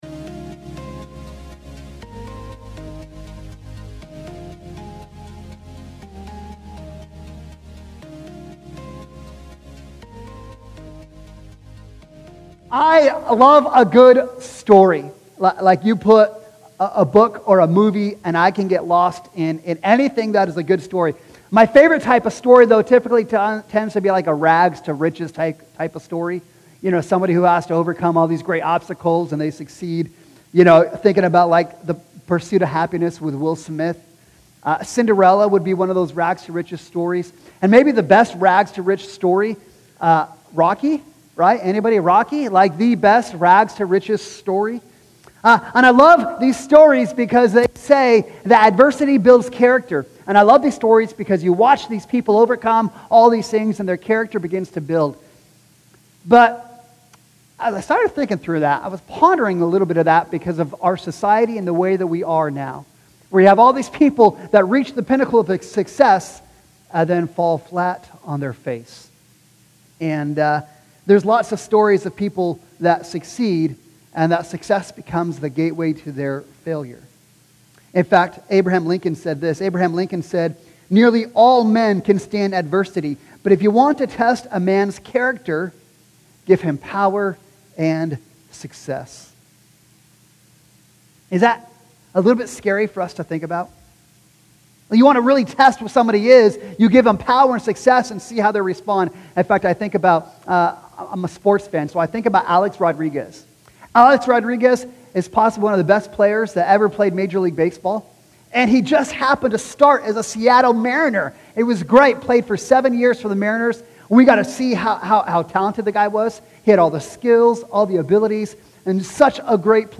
Weekly sermons from Restoration Church of Yakima